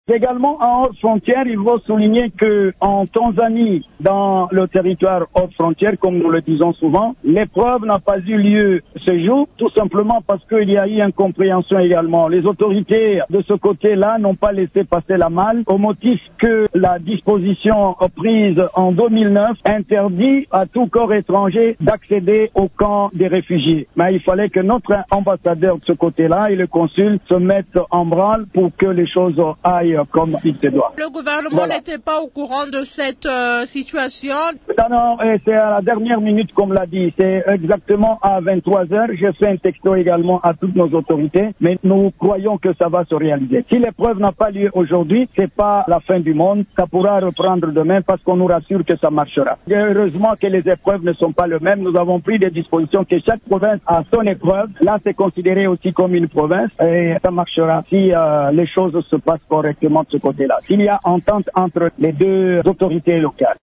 Dans cet extrait sonore, il affirme que ces épreuves pourraient reprendre ce mardi :